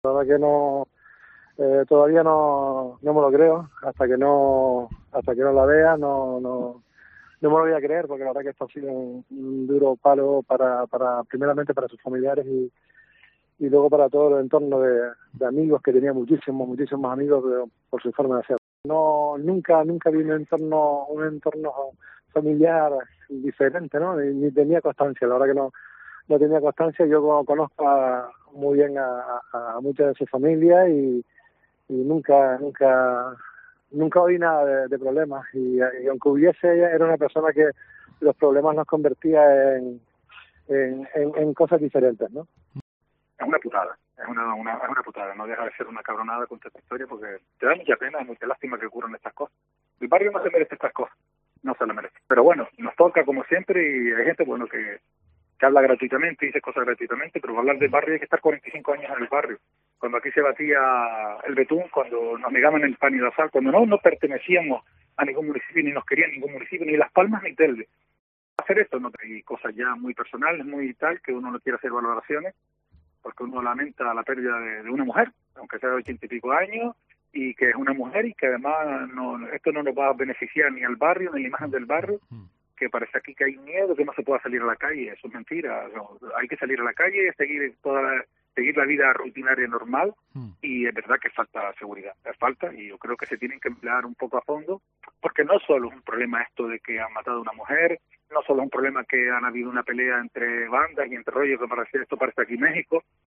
Jinámar sigue conmocionado por el doble episodio de sangre y muerte.